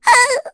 Mediana-Damage_01.wav